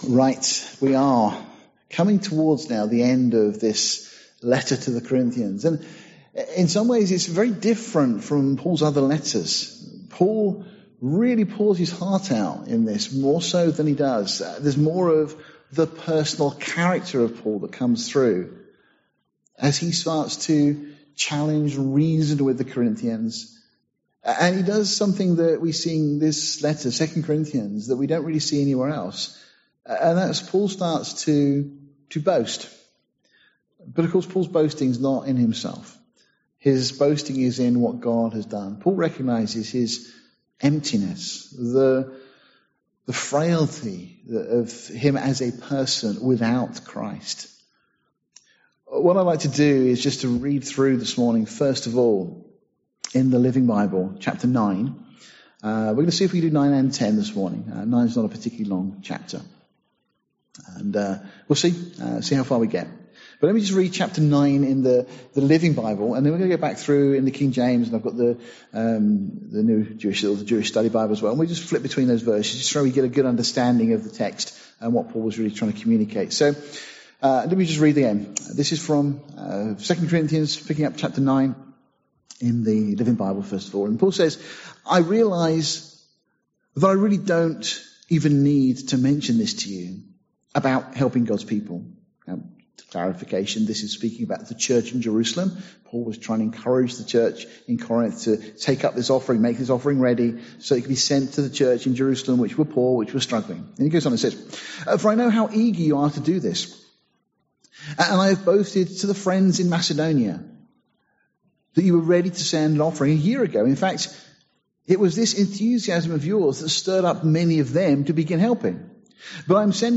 Series: Sunday morning studies Tagged with verse by verse